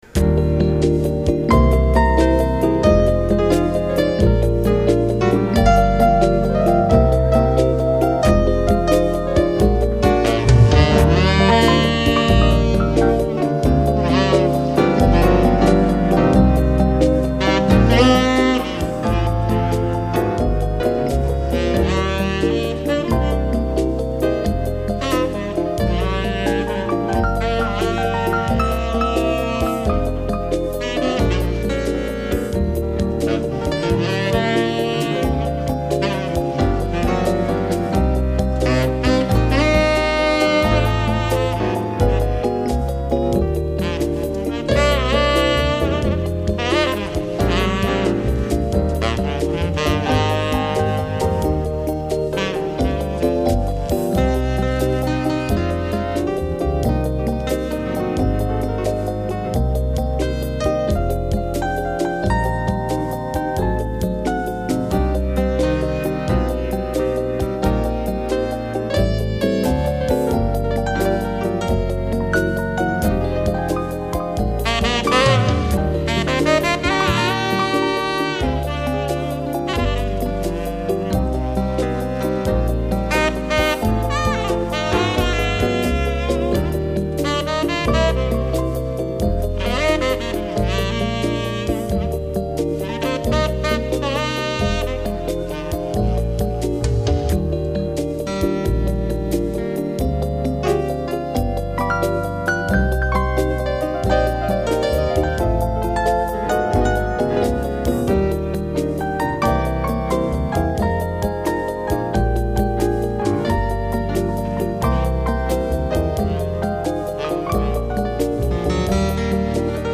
keyboard
trumpet, guitar, vocals
saxophones
trombone